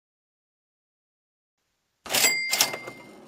Cha Ching Basketball Sound Button - Botón de Efecto Sonoro